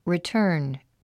発音
ritə’ːrn　リィターン